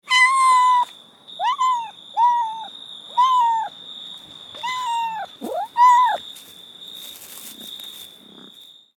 دانلود صدای میو میو بچه گربه از ساعد نیوز با لینک مستقیم و کیفیت بالا
جلوه های صوتی